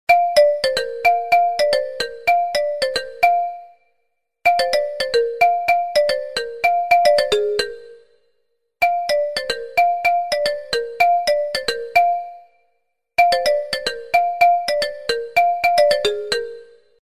В подборке — короткие и узнаваемые сигналы, которые помогут настроить мессенджер под ваш стиль.
Viber уведомление